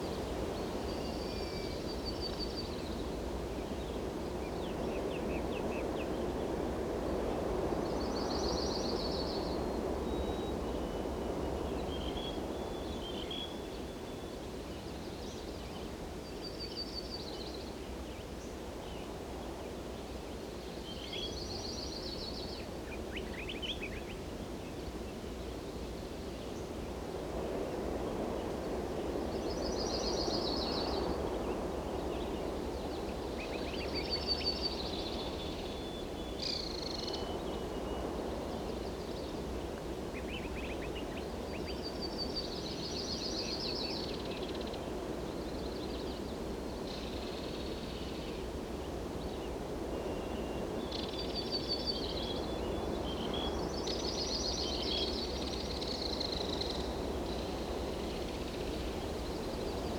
Forest Day.ogg